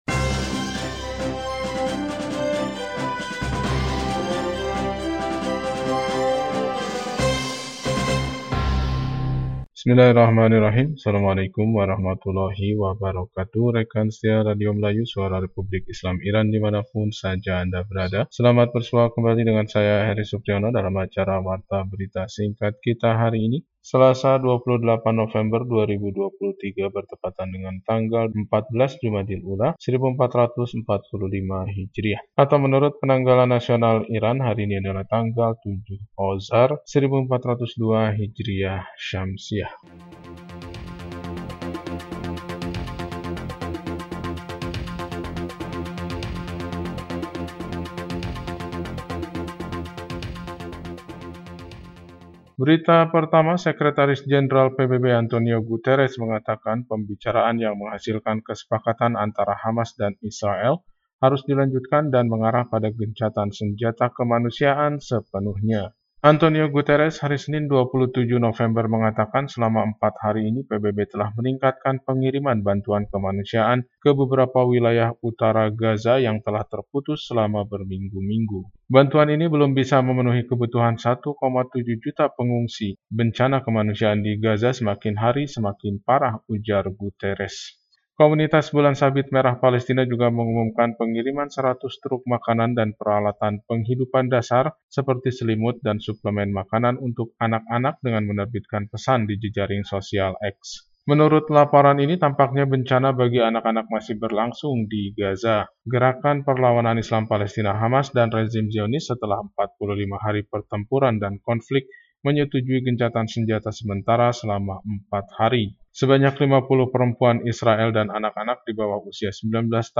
Warta Berita 28 November 2023